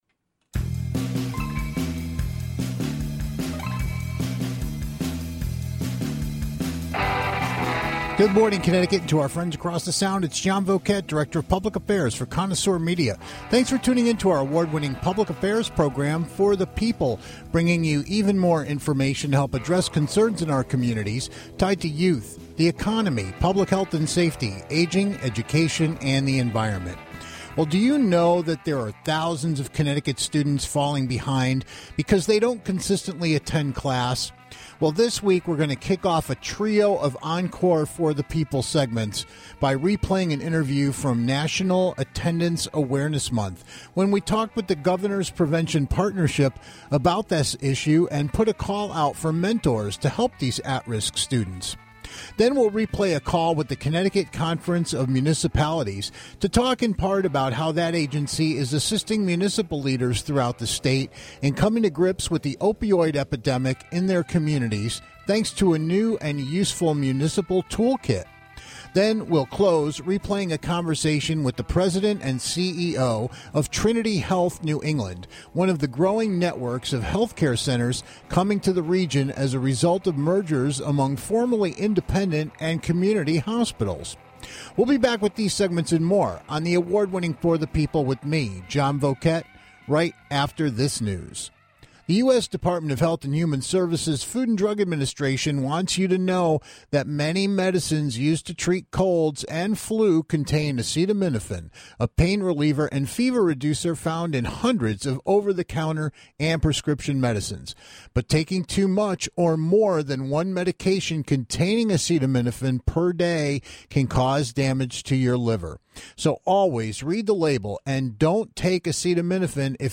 Then we replay a call with the Connecticut Conference of Municipalities discussing how that agency is assisting municipal leaders coming to grips with the opioid epidemic with a new, useful toolkit.